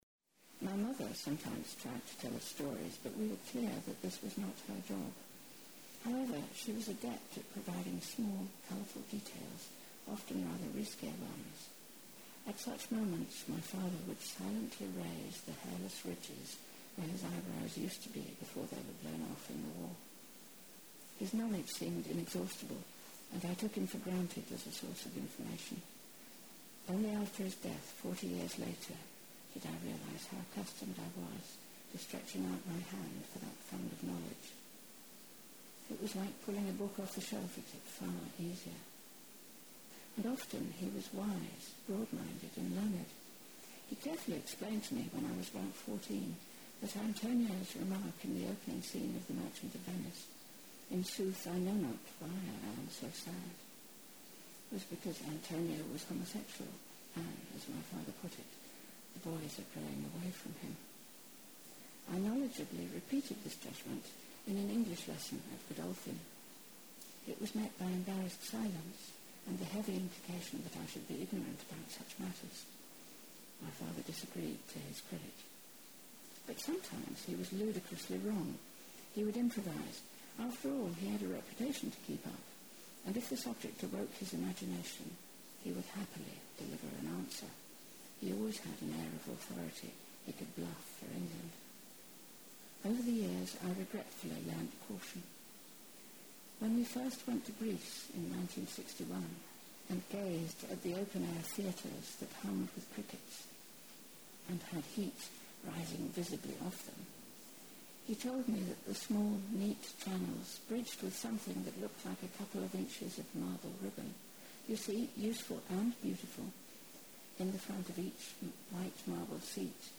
reading extracts from the book
Reading 3 “My mother sometimes tried to tell us stories but we were clear that this was not her job…”